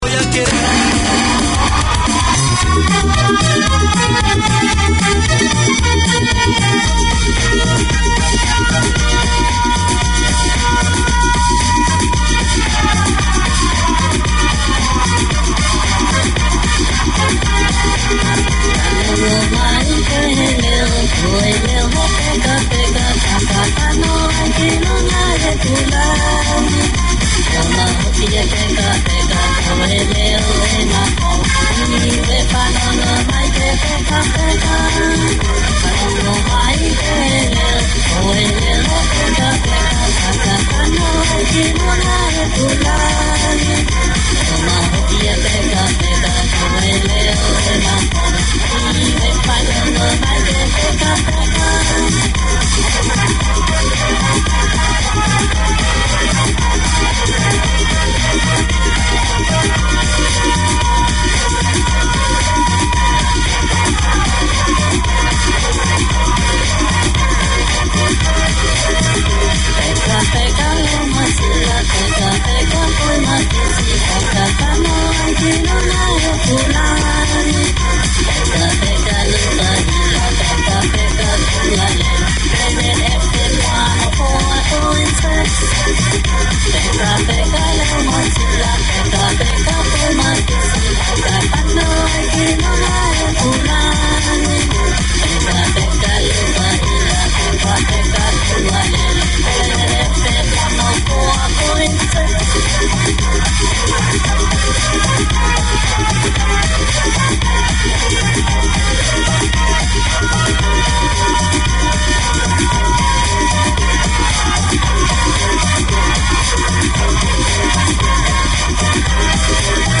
Music, global news and interviews with people from Niue and the Niuean community feature in this weekly hour.